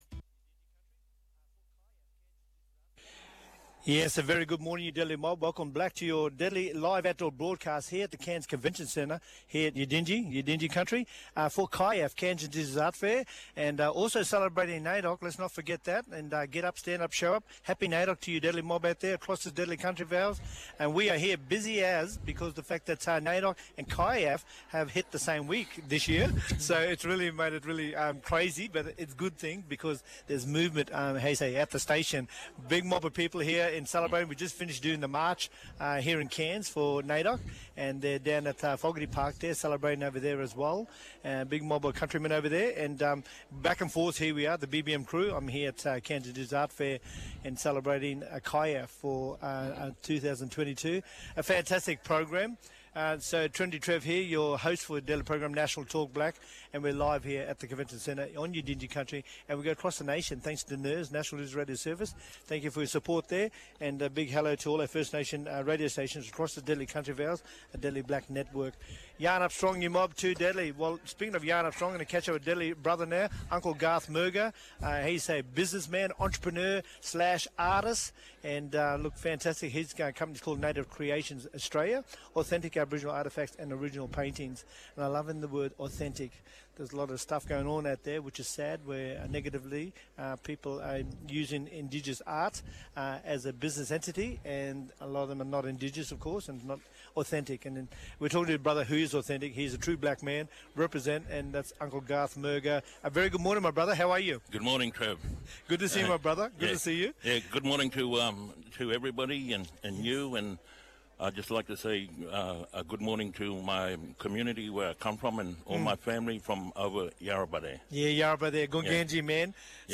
Listen back on Talkblack live from BBM’s live outdoor broadcast from Cairns Indigenous Art Fair.